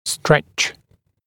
[streʧ][стрэч]растягивать, растягиваться